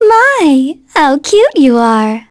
Cassandra-vox-get_04.wav